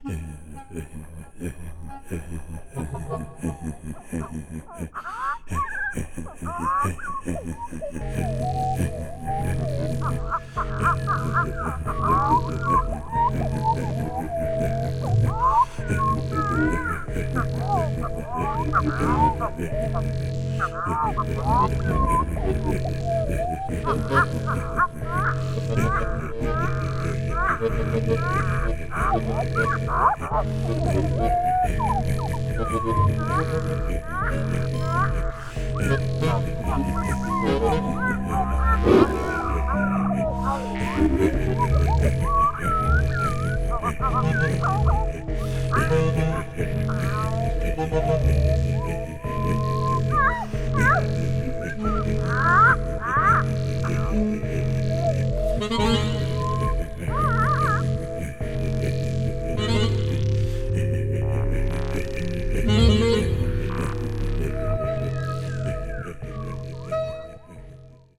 Icterine warbler, French cicadas
V-Accordion
bass clarinet, clarinet, iPad, creatures
voice
Recorded at Dreamland Studios, West Hurley, NY, August 2013